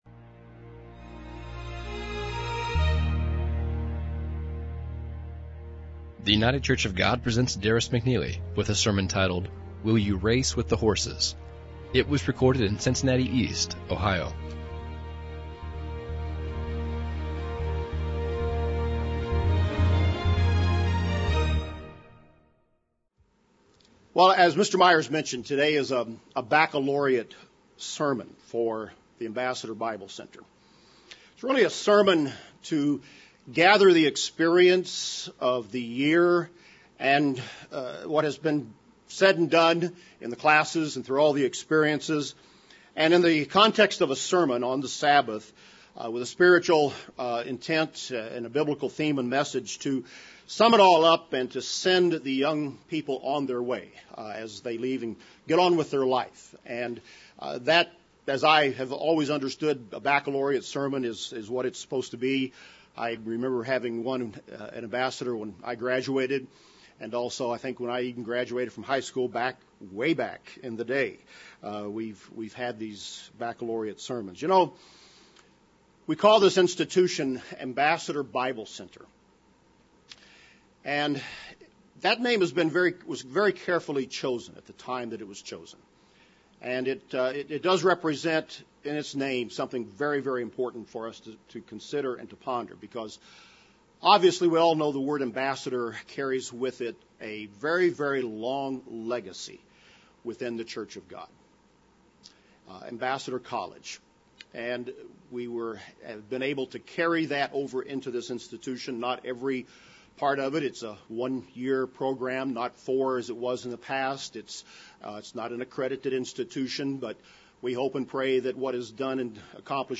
A Baccalaureate sermon for the Ambassador Bible Center to gather the experience of the year and what has been said and done in the classes through all the experiences and in the context of a sermon on the Sabbath with spiritual intent in the biblical theme and message to sum it all up and send the young people on their way as they leave and get on with their life.